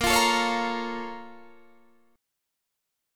A#mM7 Chord
Listen to A#mM7 strummed